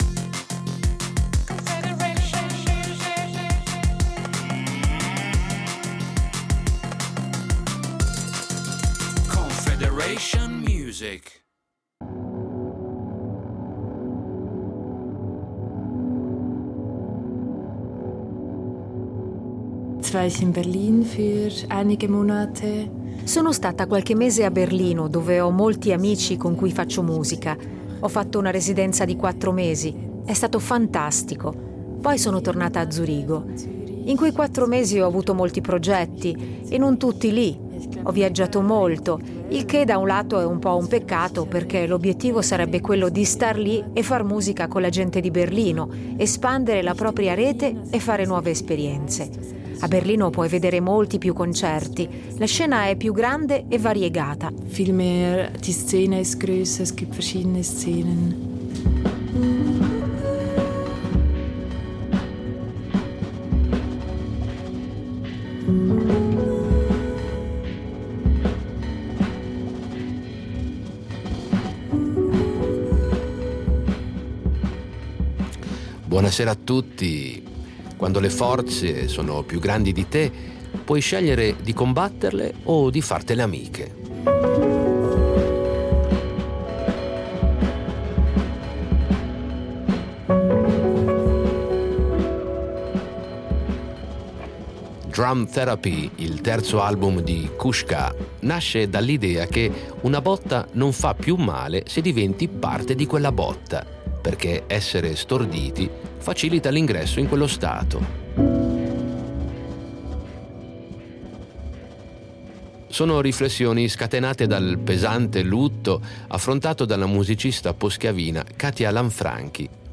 Musica pop
Le canzoni non sono più solo canzoni, sono riflessioni sul fatto che esistano, sul motivo per cui si persegue un obbiettivo musicale. Kusk K oggi sono concettuali e sperimentali.